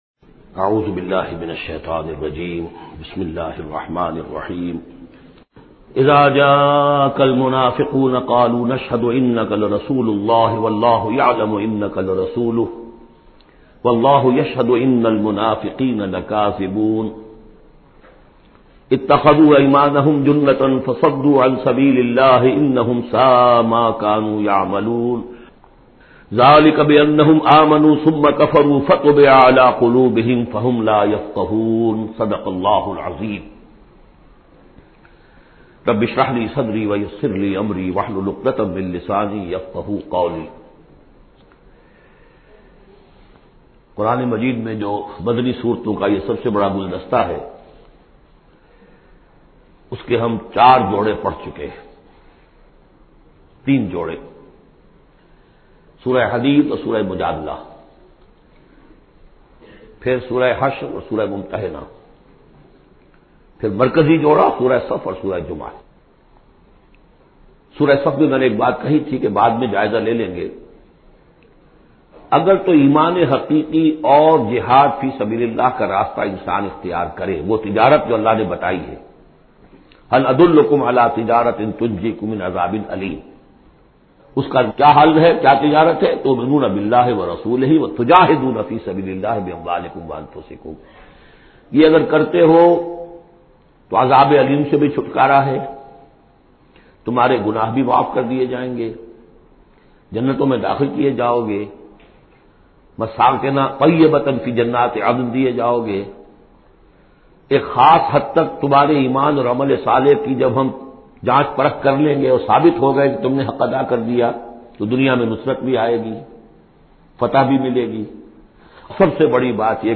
Surah Munafiqun, listen online mp3 audio tafseer in the voice of Dr Israr Ahmed.